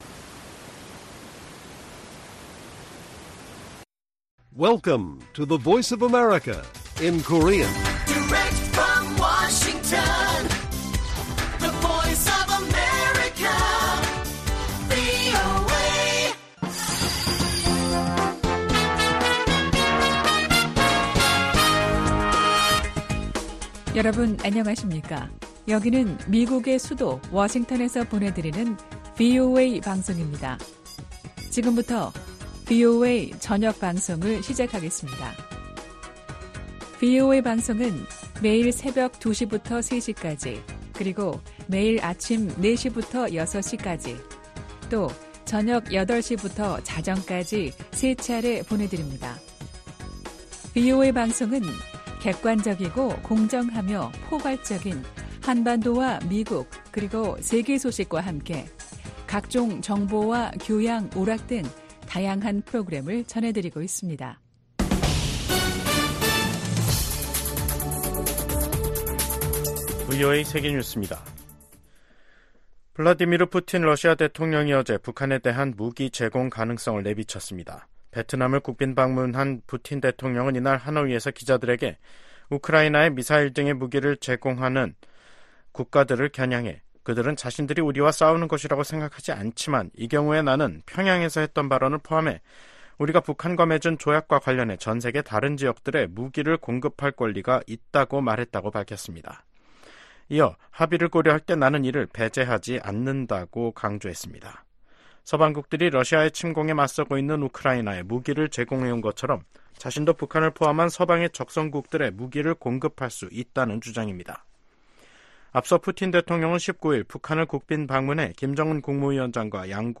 VOA 한국어 간판 뉴스 프로그램 '뉴스 투데이', 2024년 6월 21일 1부 방송입니다. 북한과 러시아가 군사협력 조약을 체결한 데 대해 미국 백악관과 국무부, 국방부등이 우려를 나타냈습니다.